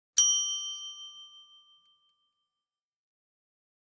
Order-up-bell-sound-effect.mp3